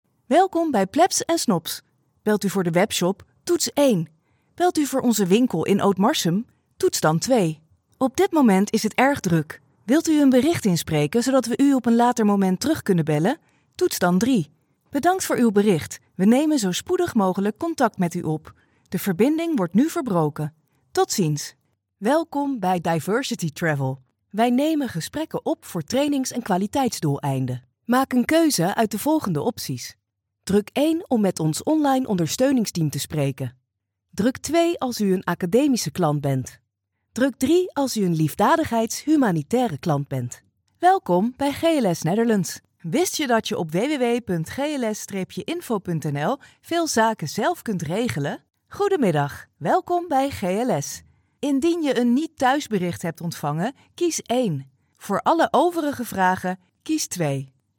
Natuurlijk, Speels, Toegankelijk, Veelzijdig, Vriendelijk
Telefonie